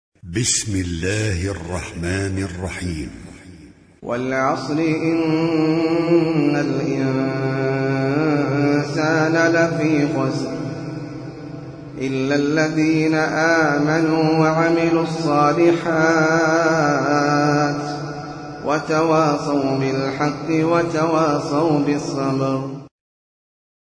Al-Mus'haf Al-Murattal